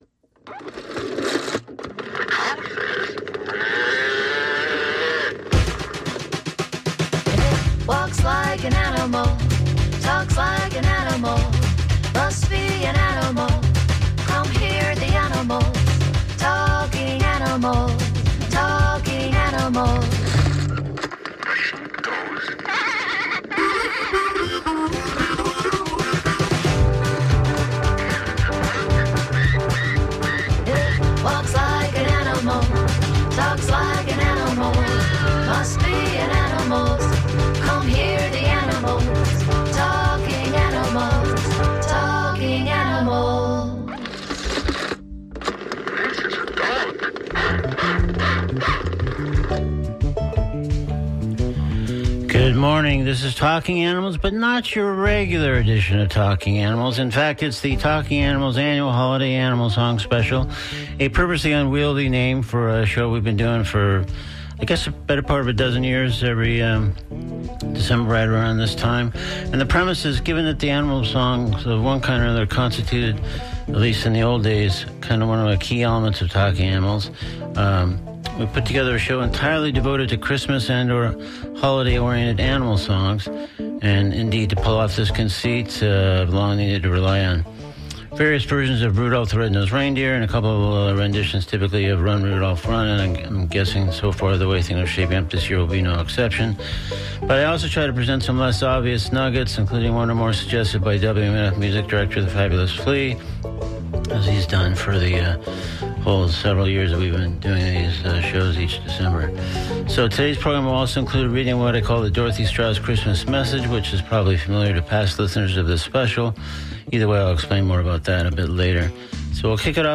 TADec18HolidayAnimalSongs.mp3